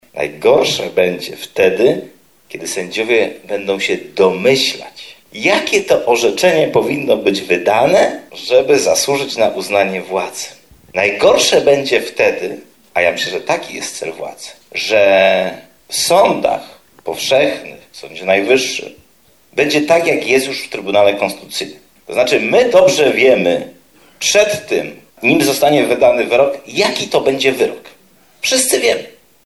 Presja jakiej są poddawani sędziowie, jak zaznaczał w Janosiku Krystian Markiewicz, jest jednak ogromna.